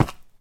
stone3.ogg